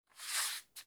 slide (1).wav